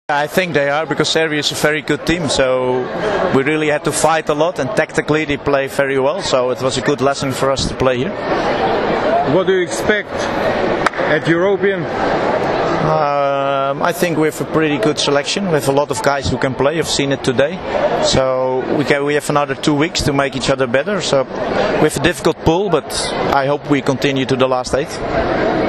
IZJAVA GIDA VERMELENA